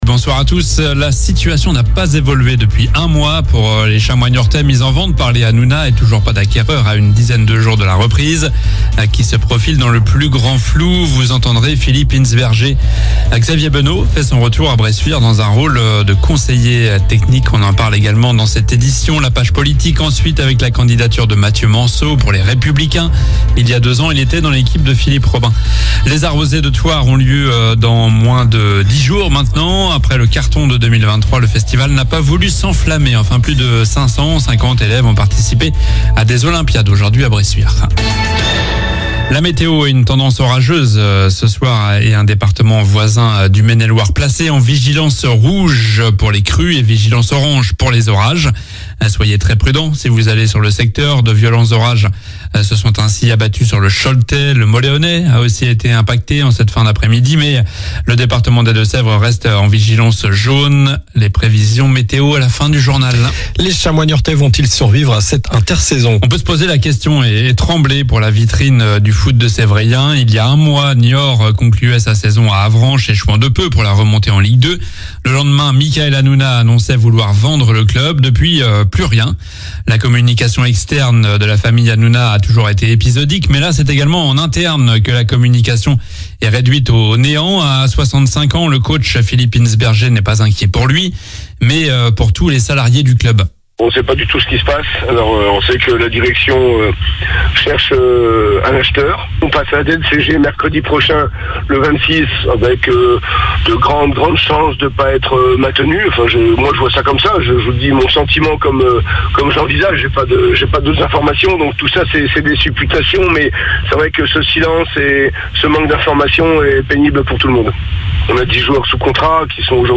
Journal du jeudi 20 juin (soir)